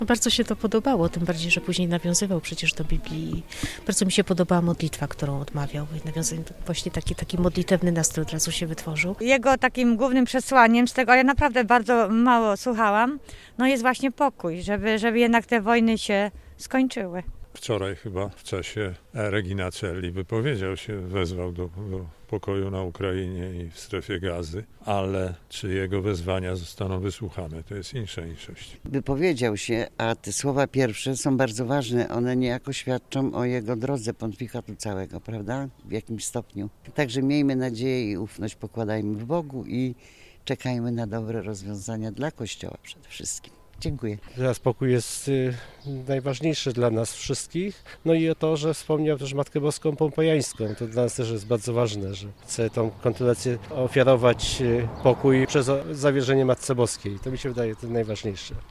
Zdaniem mieszkańców stolicy Dolnego Śląska pierwsze słowa mogą wskazywać na co Leon XIV będzie kładł nacisk w czasie swojego pontyfikatu.